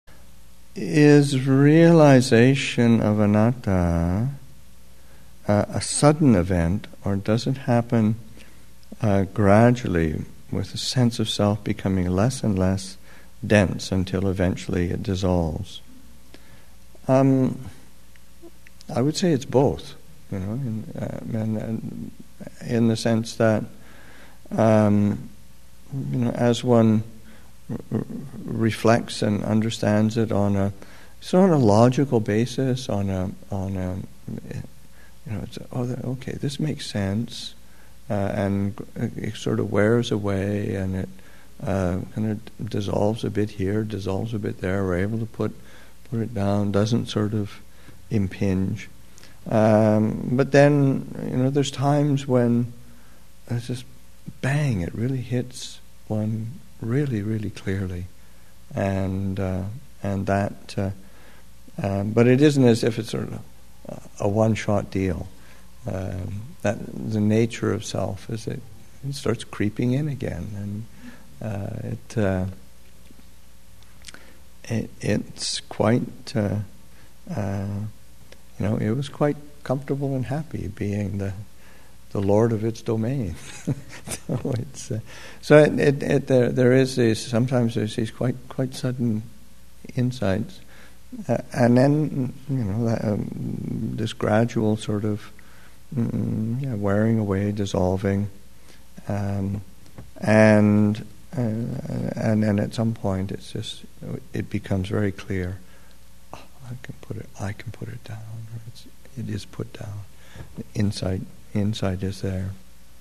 Thanksgiving Retreat 2016, Session 7, Excerpt 4